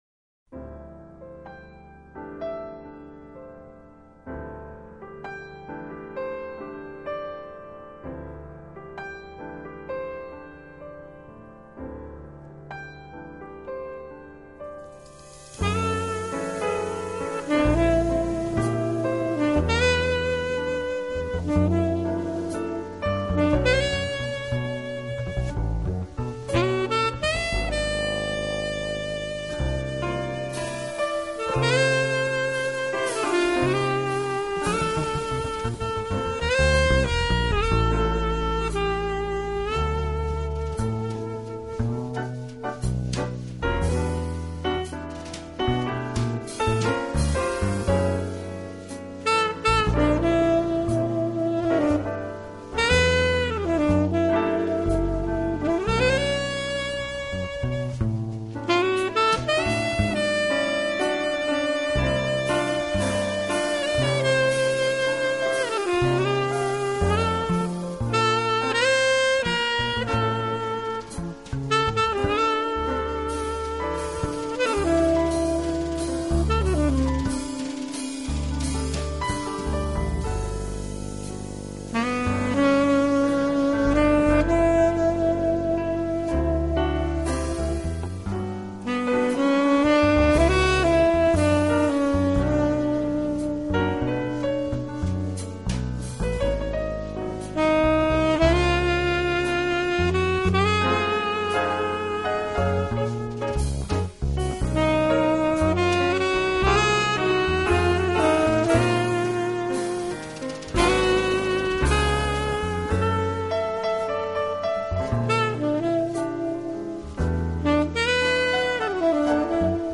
Smooth Jazz
他的演奏风格包容了Jazz、R&B、Pop，不